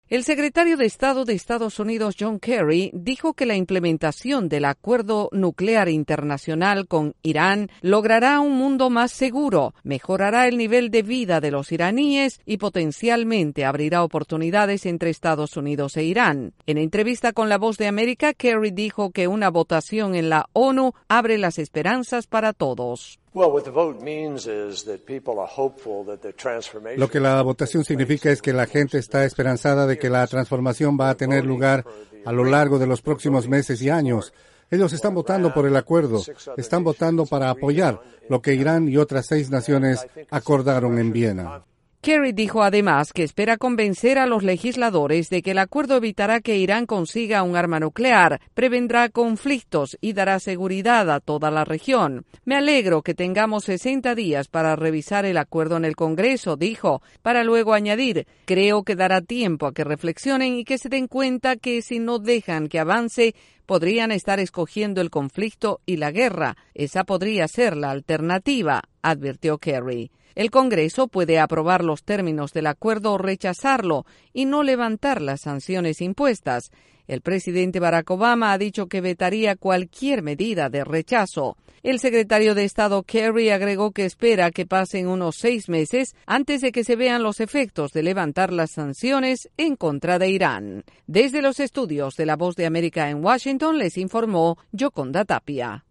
El secretario de Estado, John Kerry, afirma en entrevista con la Voz de América su esperanza en que el Congreso evaluará de manera positiva el acuerdo nuclear con Irán.